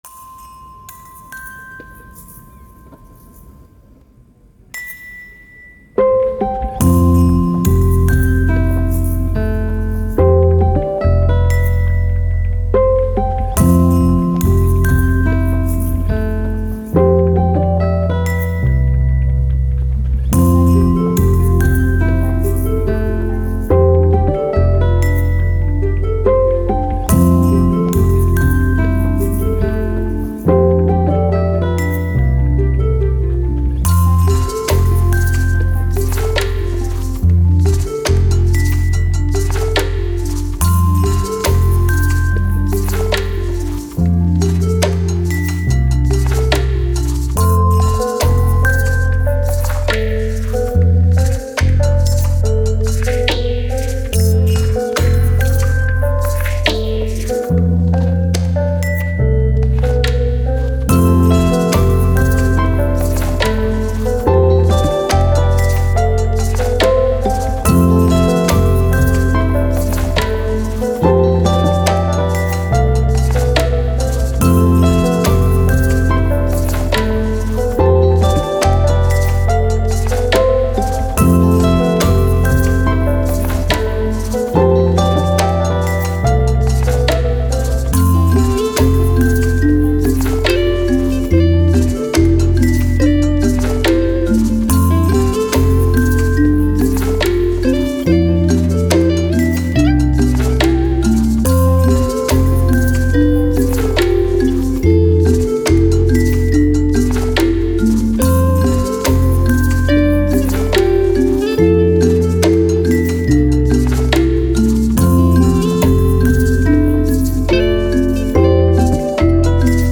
Ambient, Downtempo, Soundtrack, Minimal